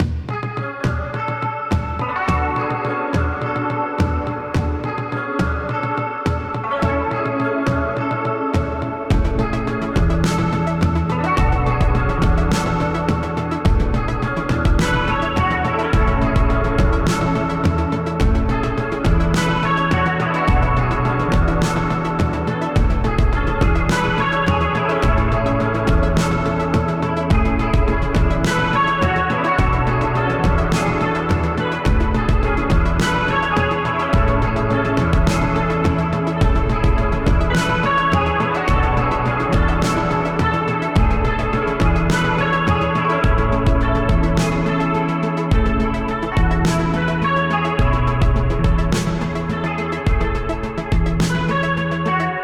extrem geile 80er Sounds :D
da hört sich vieles wie die vorredner auch gepostet ham eher nach gitarren an... die sind teilw. mit synthisounds gedoppelt. vom synthisound her erinnert mich das am ehsten an nen oberheim oder so. hab mal 2 presets gespielt und in die hallgeräte geschickt: